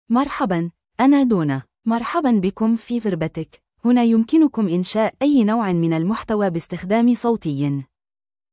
DonnaFemale Arabic AI voice
Donna is a female AI voice for Arabic (Standard).
Voice sample
Listen to Donna's female Arabic voice.
Donna delivers clear pronunciation with authentic Standard Arabic intonation, making your content sound professionally produced.